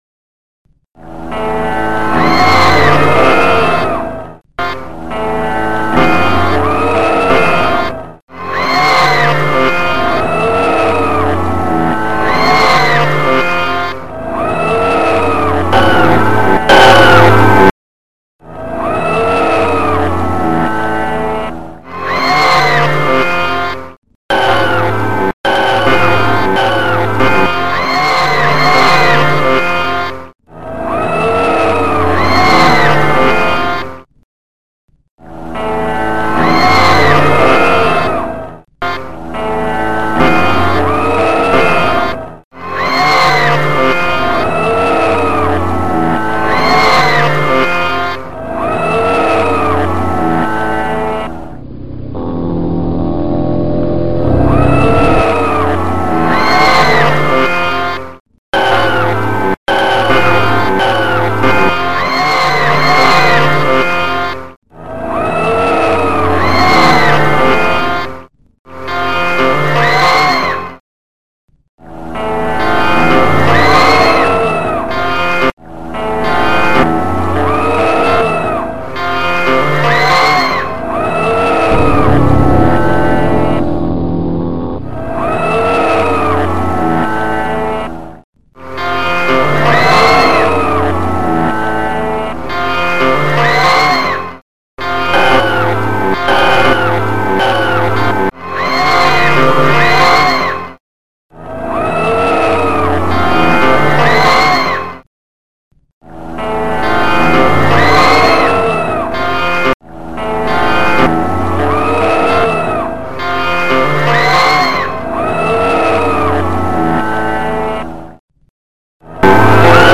you must find the origin through the static, it is something that relates to this